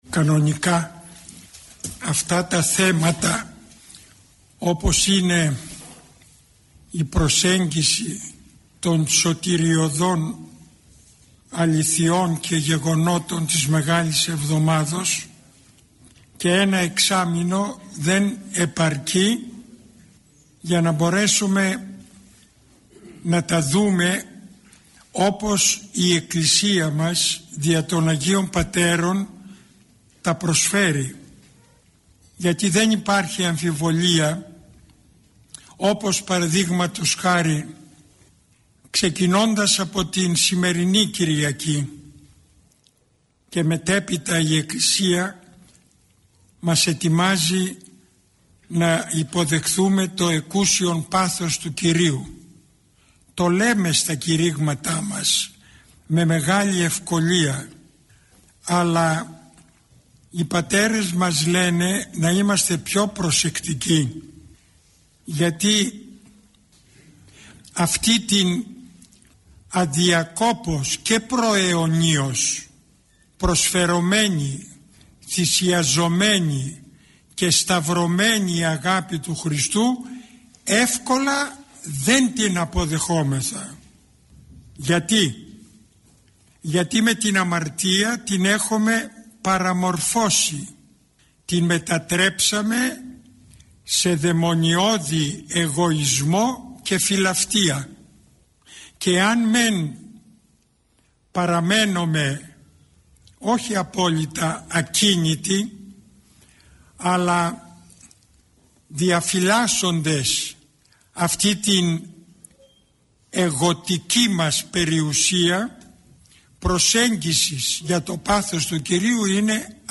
Η ομιλία αυτή “δόθηκε” στα πλαίσια του σεμιναρίου Ορθοδόξου πίστεως – του σεμιναρίου οικοδομής στην Ορθοδοξία.
Το σεμινάριο αυτό διοργανώνεται στο πνευματικό κέντρο του Ιερού Ναού της Αγ. Παρασκευής (οδός Αποστόλου Παύλου 10), του ομωνύμου Δήμου της Αττικής.